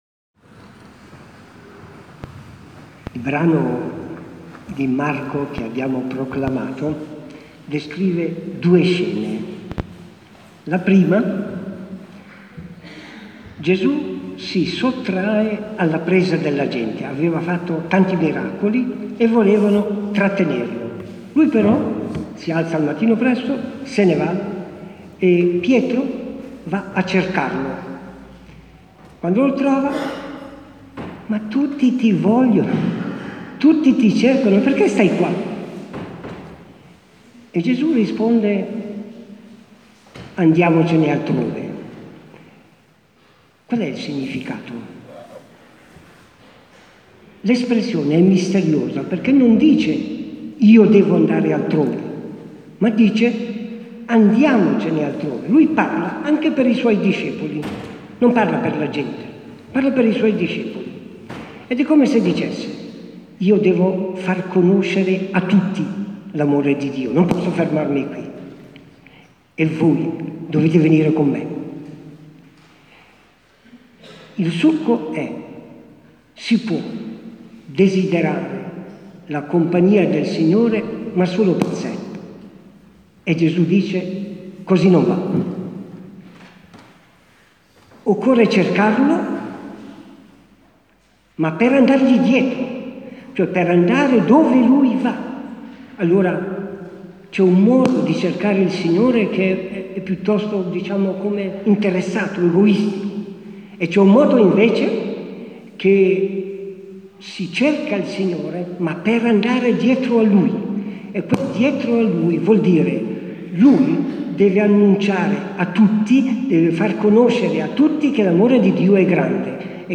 Riapertura della chiesa parrocchiale San Vincenzo Levita e Martire.
omelia-cremnago-2019.mp3